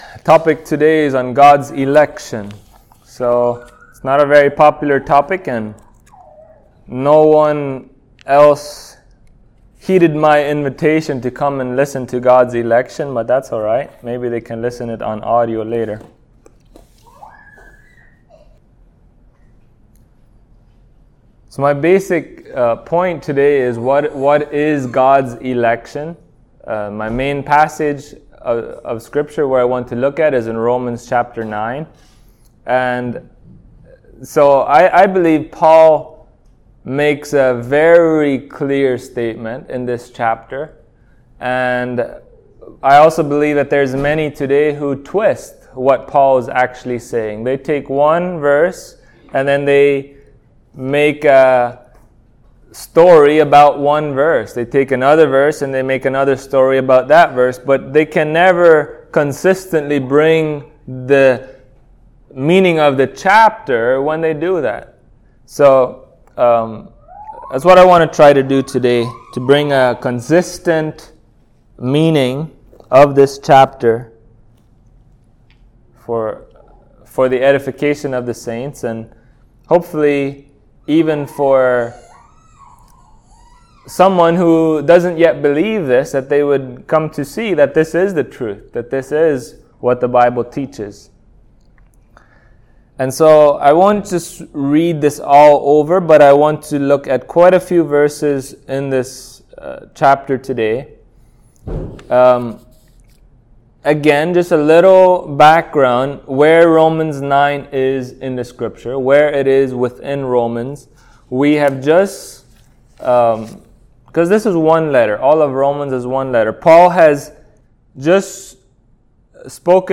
Romans Passage: Romans 9 Service Type: Sunday Morning Topics